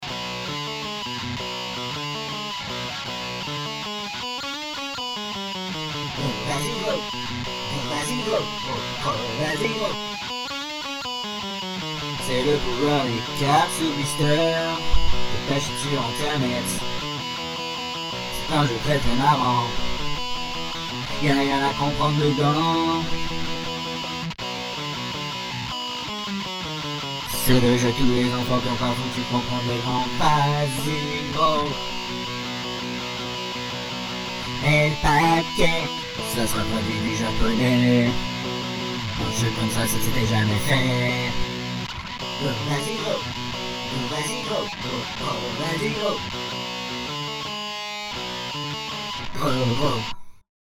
Bonus ! le générique de « VAZYGRO !